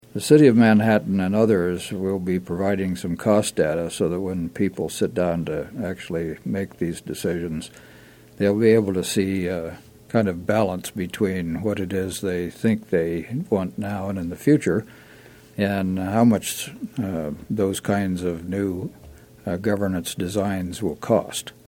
Manhattan Mayor Mike Dodson spoke with KMAN ahead of the Green Valley governance plan town hall Tuesday. He says the discussion has a couple of components to it — one being emotional, and the other being cost-based.